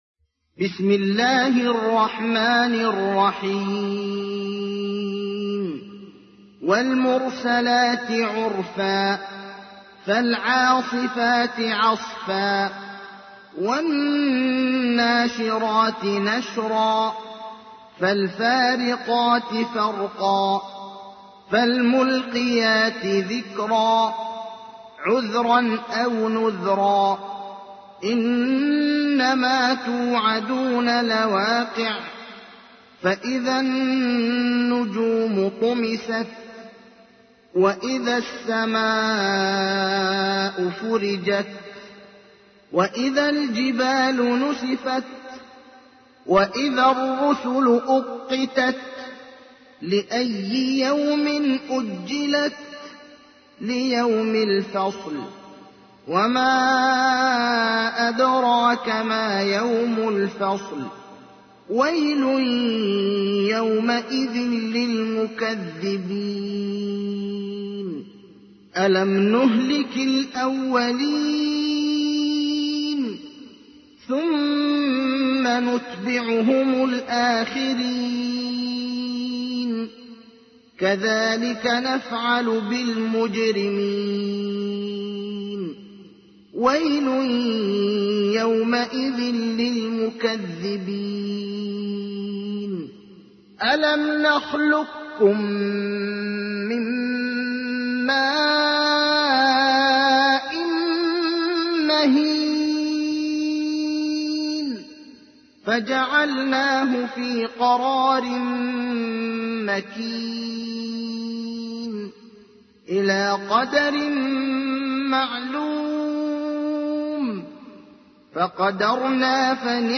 تحميل : 77. سورة المرسلات / القارئ ابراهيم الأخضر / القرآن الكريم / موقع يا حسين